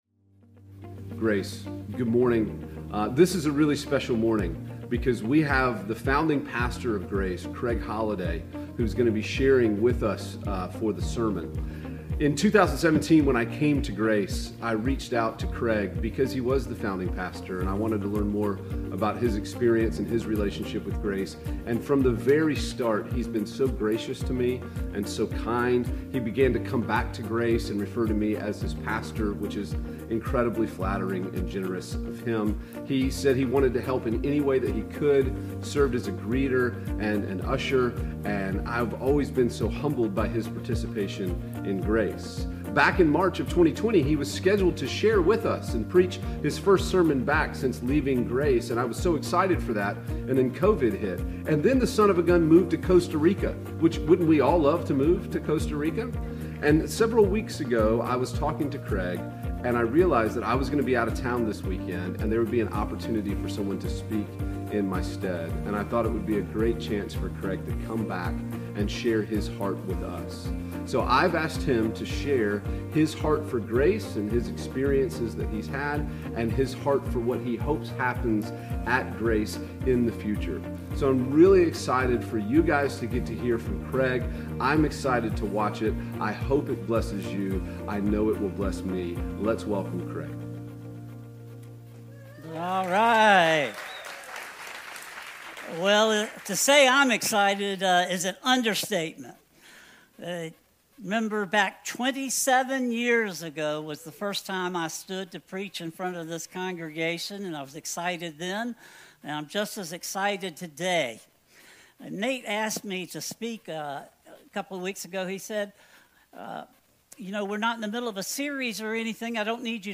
Grace Raleigh Sermons